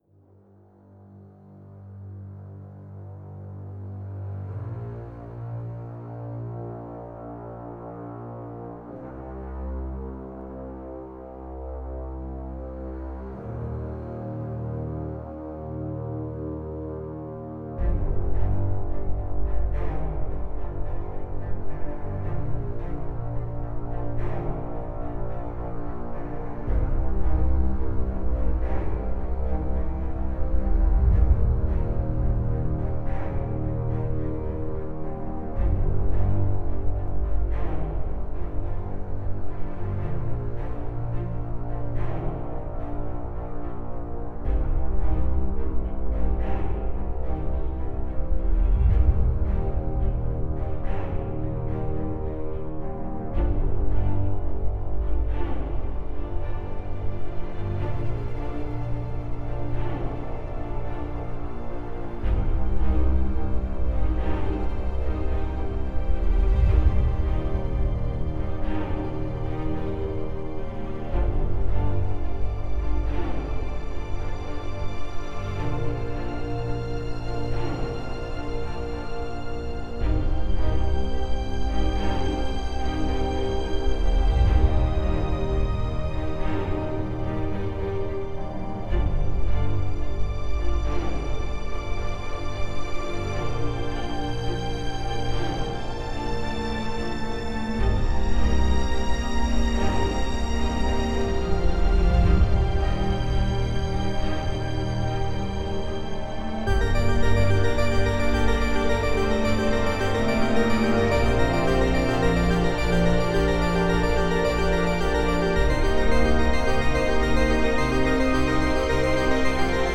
Oblivion war ehrlich gesagt auch die Inspiration für die Drums und den Synth Sound Für die Strings habe ich mehrere verwendet.
Ich habe probiert die Ratschläge zu befolgen und da kam das hier raus: Die Drums möchte ich gerne drin behalten, sonst wirkt das Stück zu langatmig.